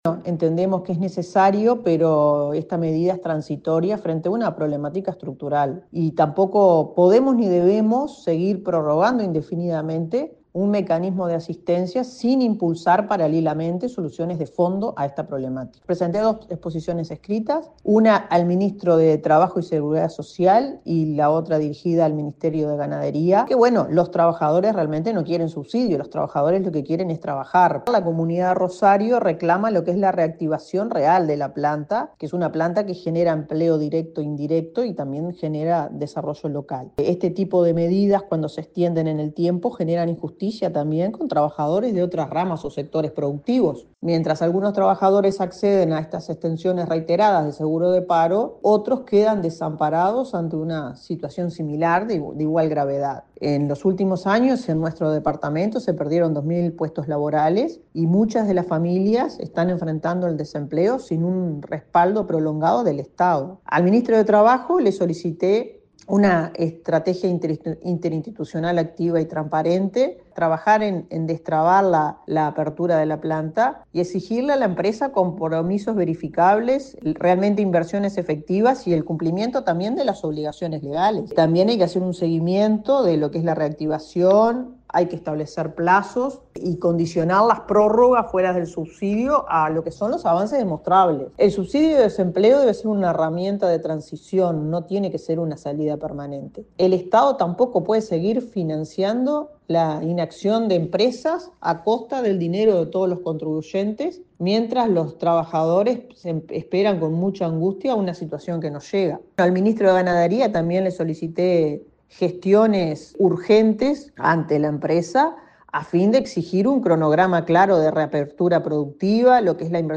La diputada colorada por Colonia, Nibia Reisch, respaldó el proyecto y valoró el apoyo a los trabajadores como una necesidad urgente, especialmente para quienes dependen del matadero Rondatel de Rosario, que permanece inactivo desde agosto del año pasado.
En sala, pidió a los ministros de Trabajo, Economía y Ganadería que se articulen medidas reales para la reactivación de las plantas y se garantice la estabilidad laboral en el largo plazo.